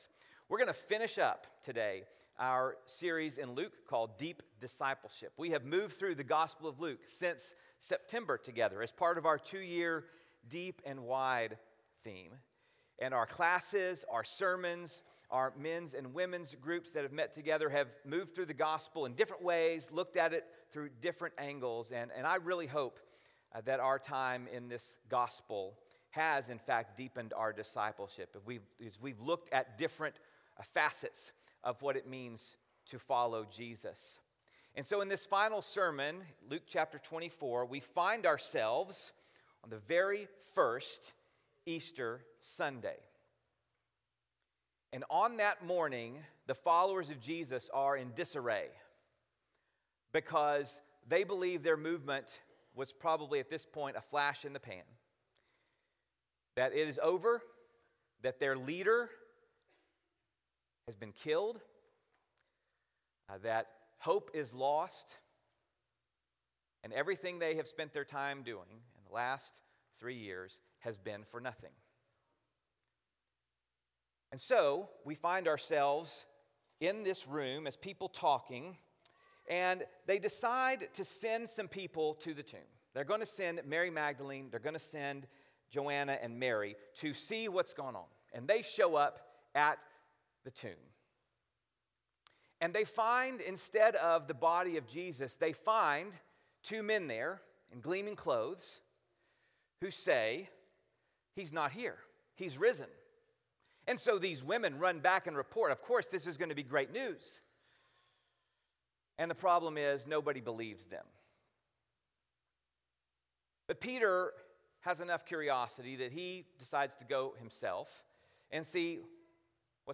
Messages
at the Preston Road Church of Christ in Dallas, Texas.